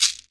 • Shaker Sound D Key 41.wav
Royality free shaker one shot tuned to the D note. Loudest frequency: 5226Hz
shaker-sound-d-key-41-Bqc.wav